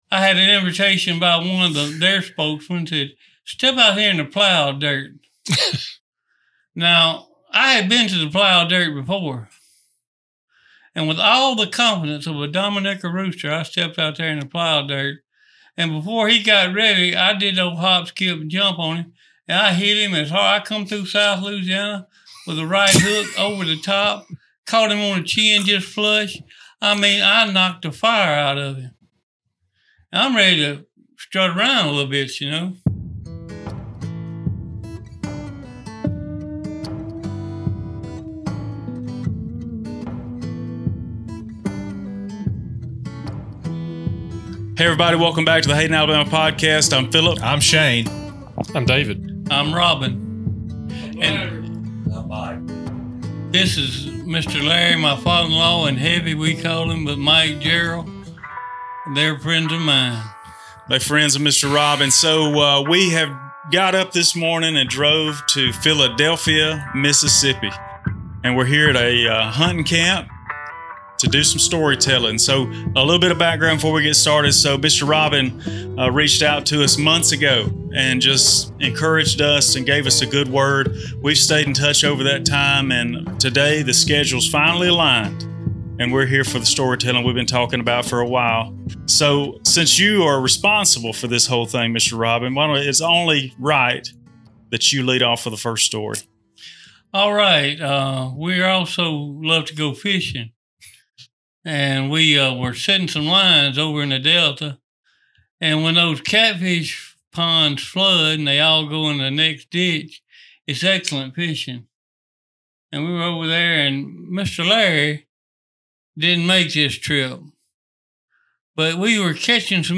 Step inside a rustic cabin off the main by-ways and next to a quaint pond. We traveled to Philadelphia, Mississippi for a storytelling this week and are sure proud that we did. From haints (haunts) to fishing and even some hunting, this episode will keep you entertained and tickled.